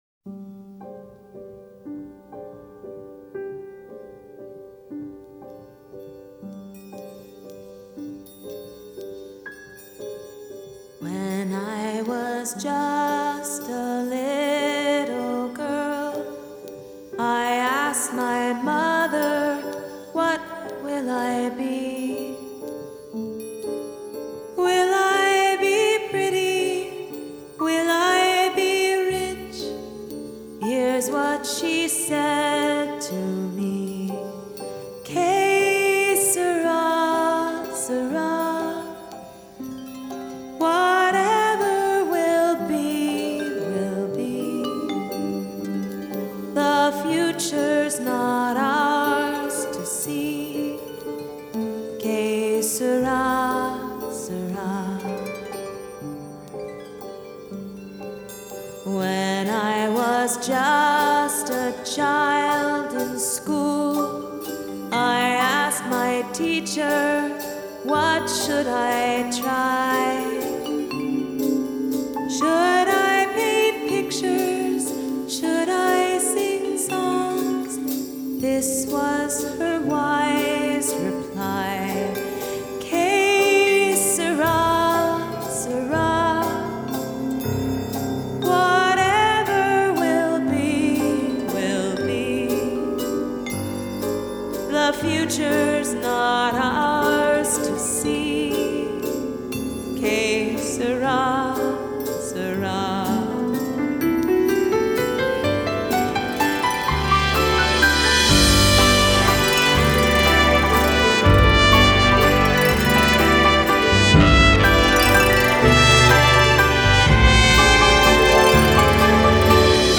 Jazz,Latin
Recorded at Stiles Recording Studio in Portland, Oregon.